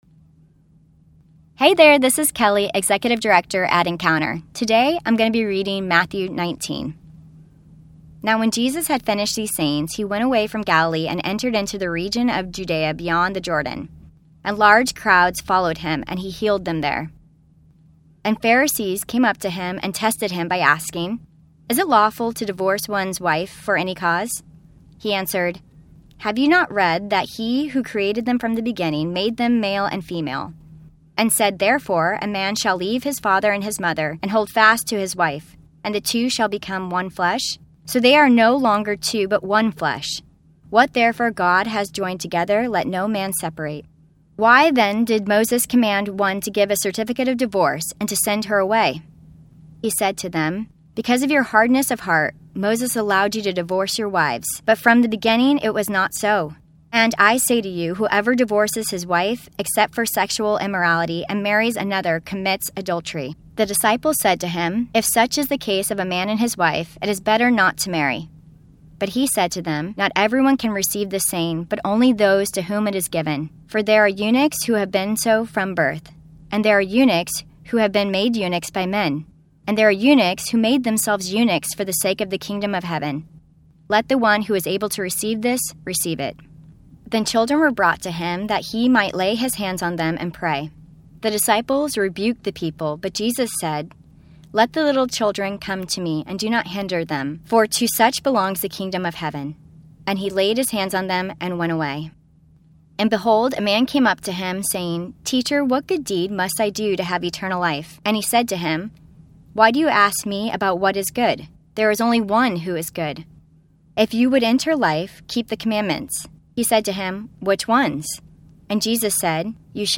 New Testament Bible Reading Plan – Audio Version